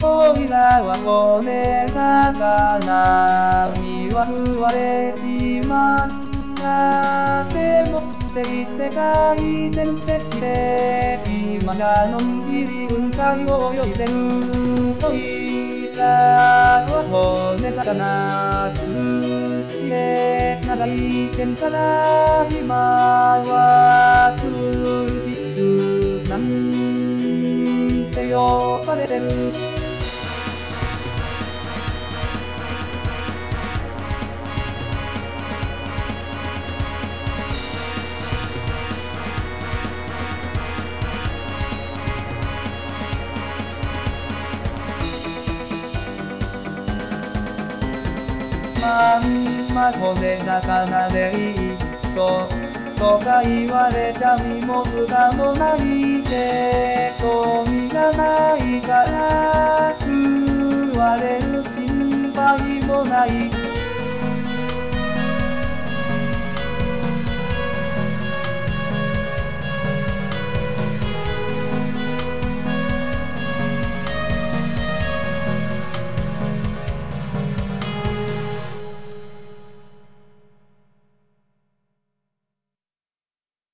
「＜++<」　andante